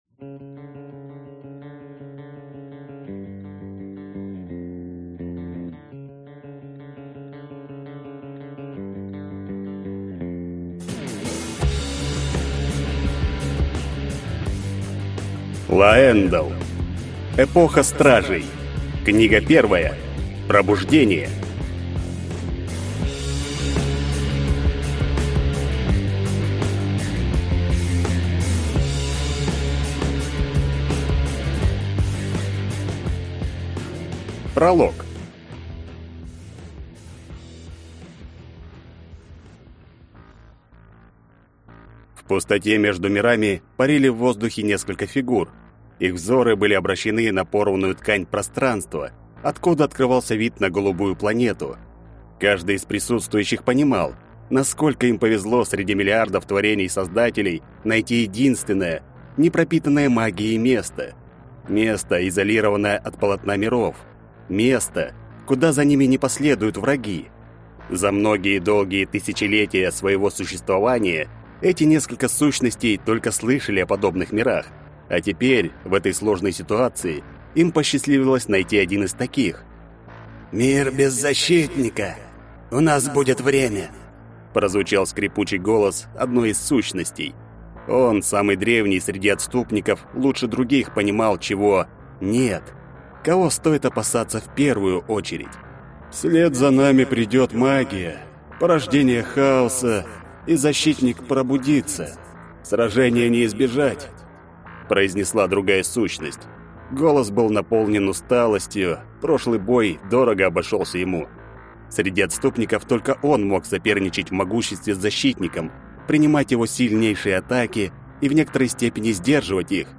Аудиокнига Пробуждение | Библиотека аудиокниг